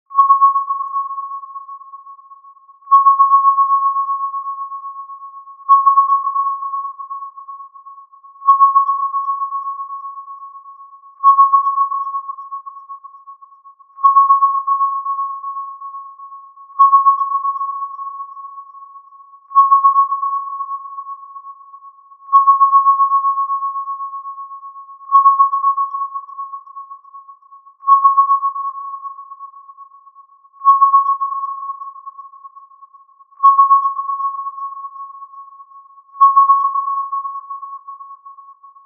ピーンピーンとエコーが効いた音。危険から身を守ろうと周囲の状況に対する注意を高める効果音。